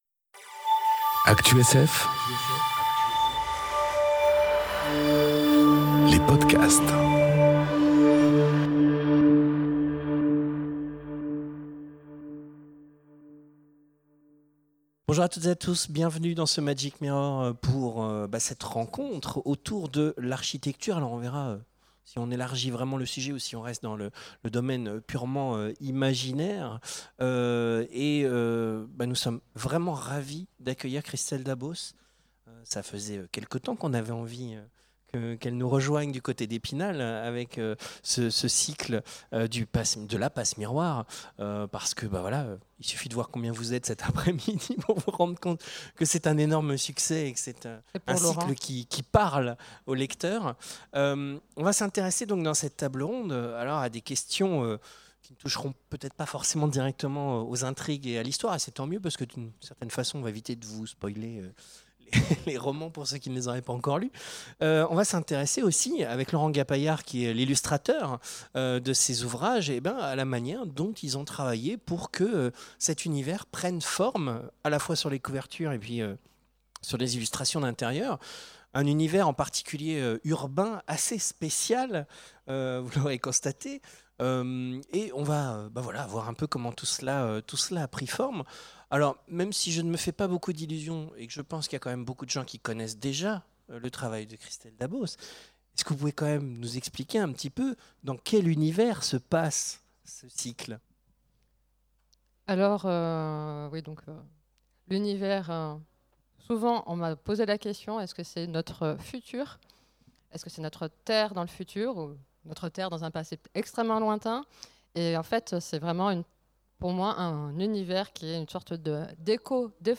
Conférence Rêver la ville... Imaginer celle de demain ! enregistrée aux Imaginales 2018